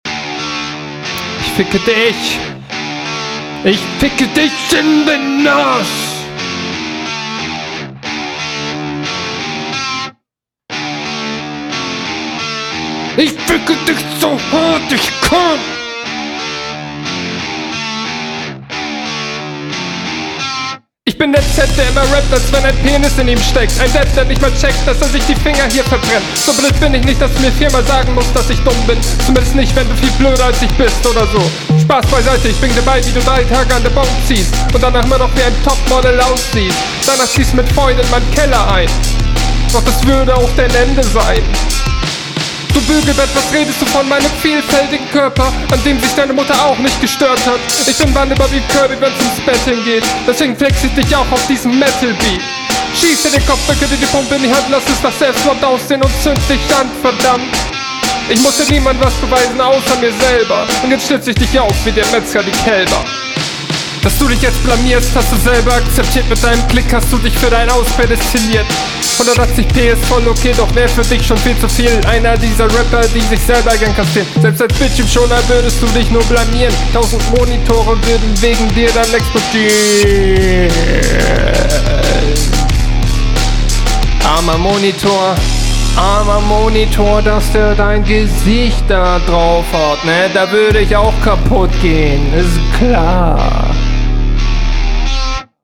Geiler Beat ! Leider versteht man die Vocal nicht so gut.